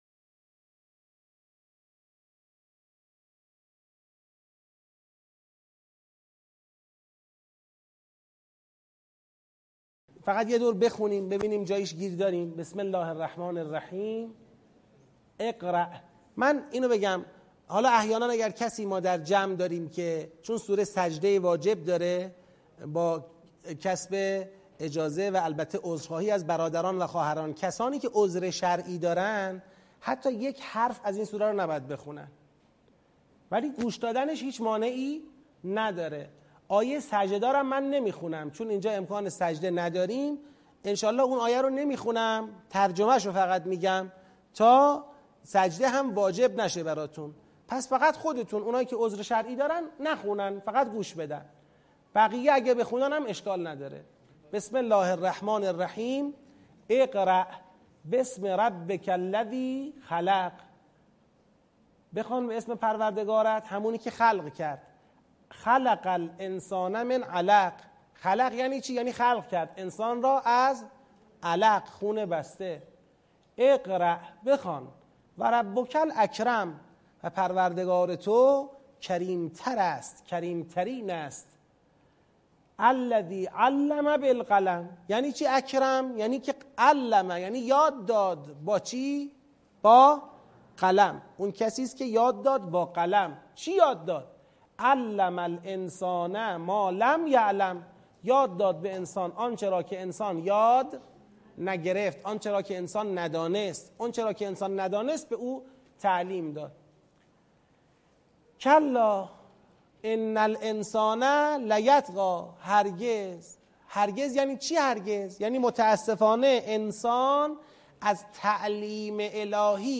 آموزش تدبر در سوره علق - بخش اول